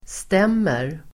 Uttal: [st'em:er]